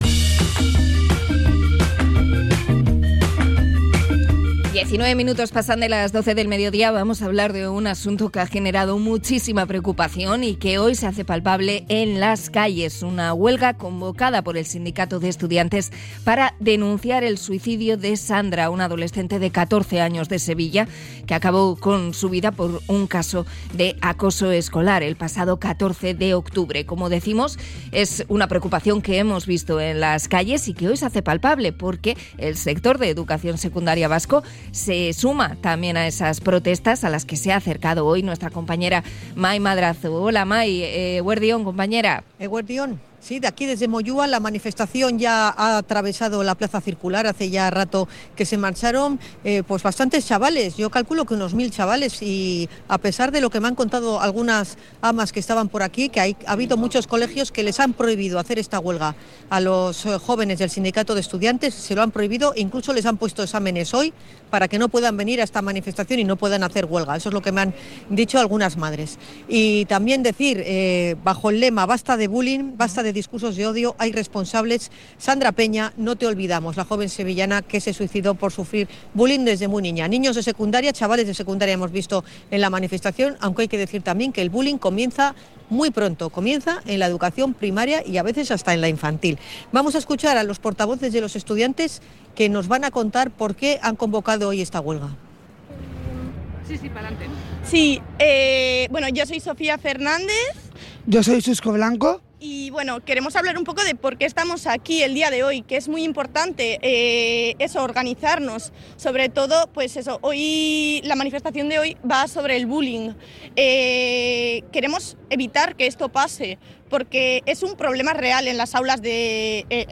Conexión con la manifestación contra el bullying